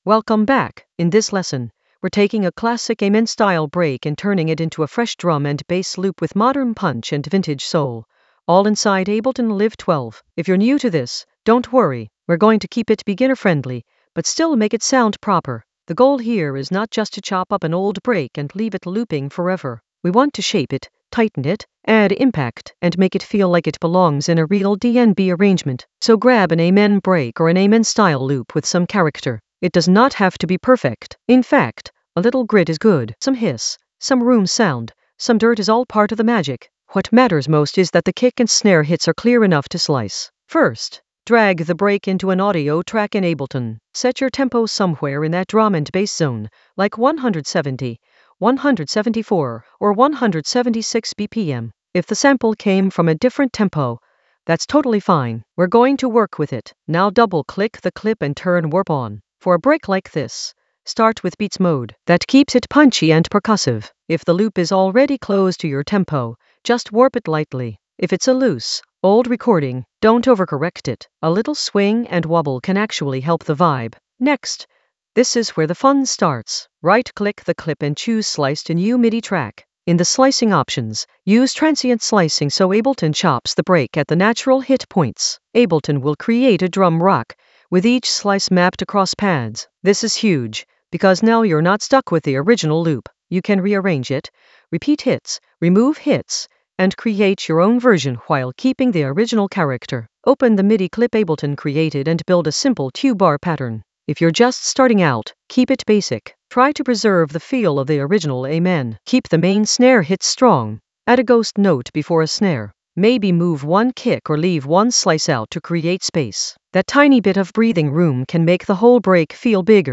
An AI-generated beginner Ableton lesson focused on Slice oldskool DnB amen variation with modern punch and vintage soul in Ableton Live 12 in the FX area of drum and bass production.
Narrated lesson audio
The voice track includes the tutorial plus extra teacher commentary.